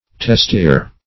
Testiere \Tes`ti*[`e]re"\, n. [OF. testiere.